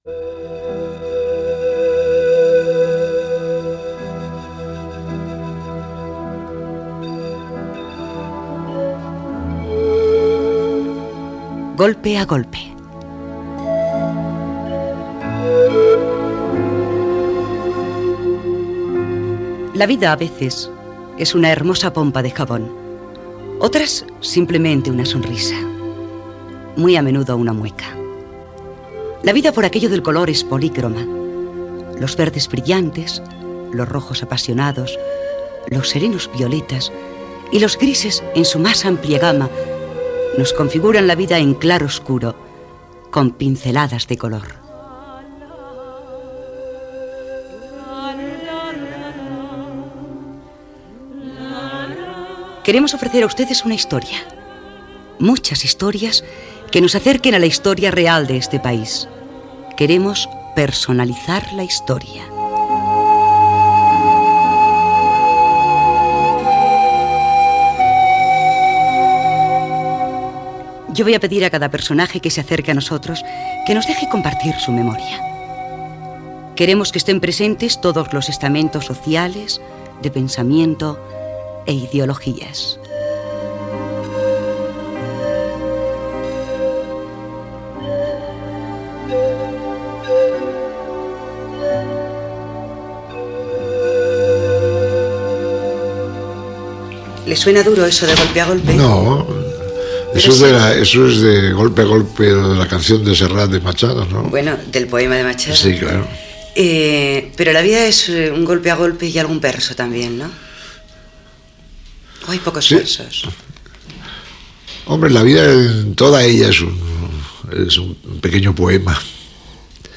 entrevista al director de cinema Juan Antonio Bardem